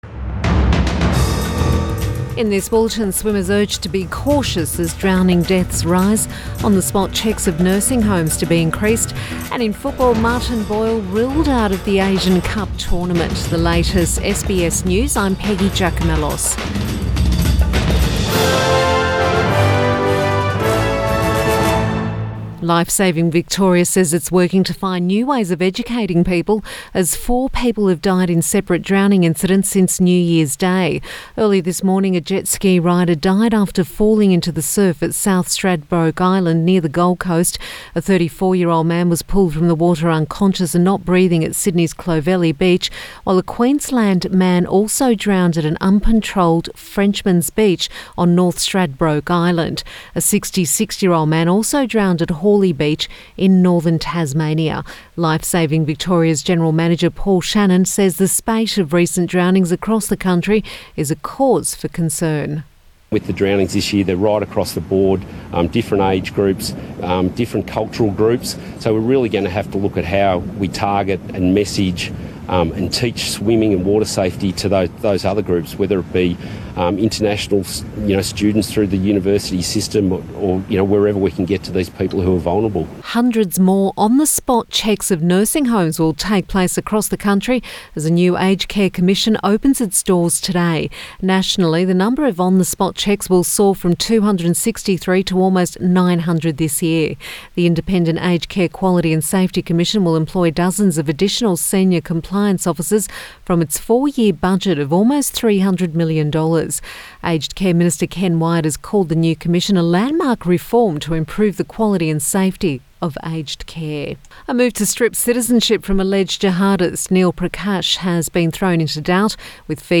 Midday Bulletin Jan 2